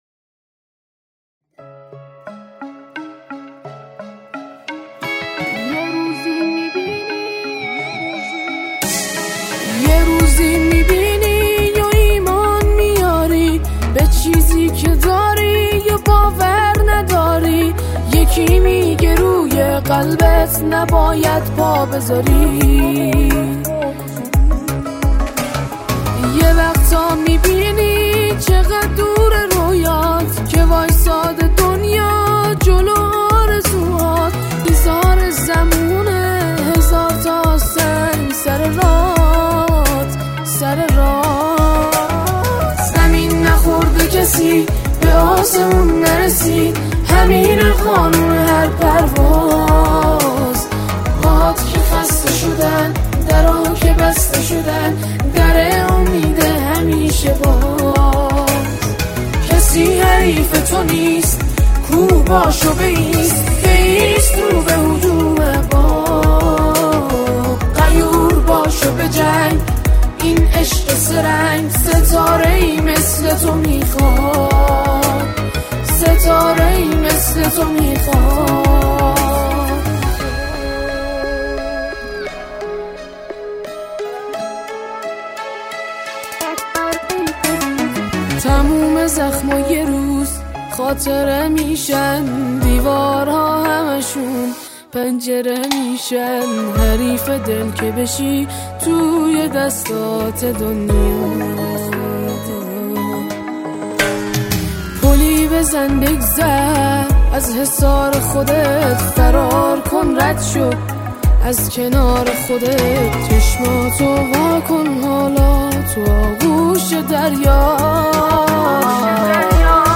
فضایی پرانرژی و انگیزشی دارد